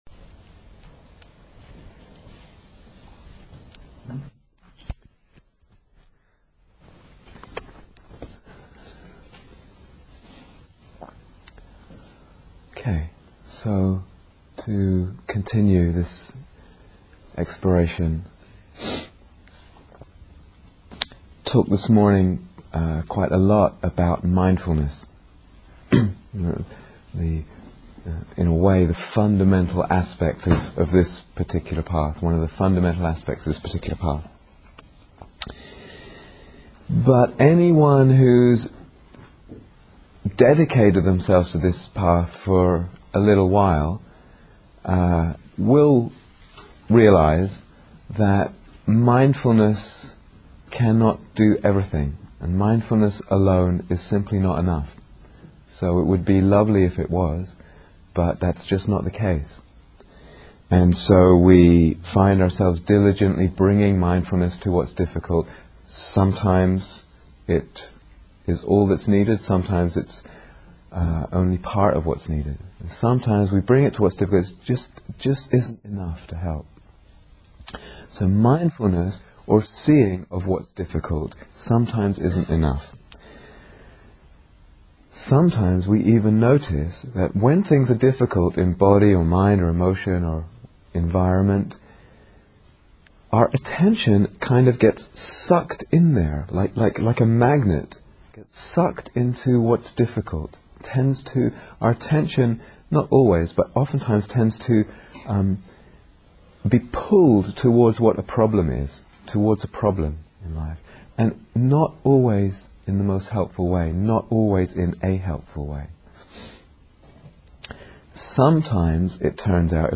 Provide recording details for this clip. Emotional Healing (Part Two) Download 0:00:00 --:-- Date 9th December 2007 Retreat/Series Day Retreat, London Insight 2007 Transcription Okay.